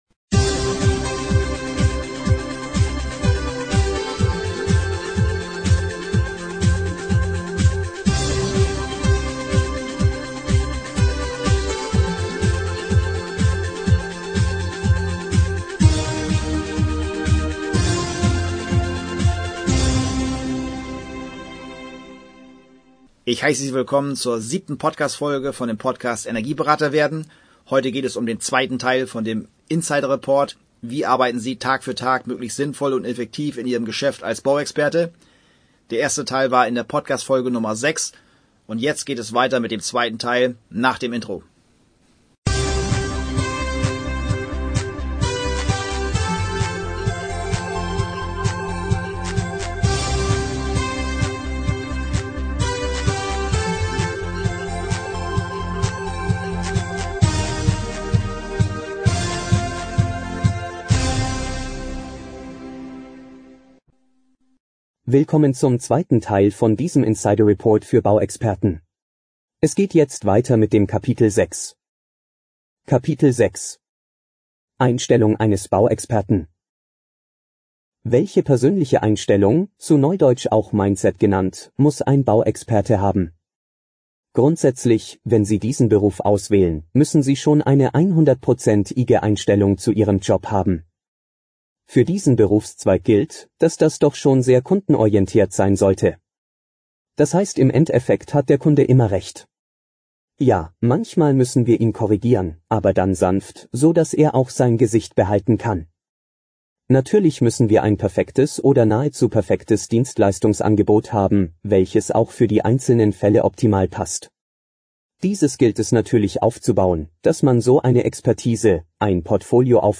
Folge #7: In dieser Podcast-Folge ist der 2. Teil meines Insider-Reportes als Hörbuch aufgenommen.